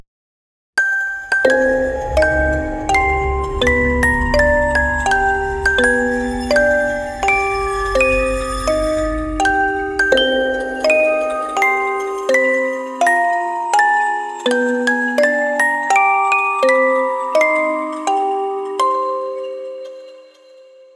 Loop